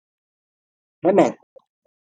Uitgespreek as (IPA) /heˈmɛn/